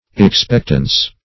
Expectance \Ex*pect"ance\, Expectancy \Ex*pect"an*cy\, n.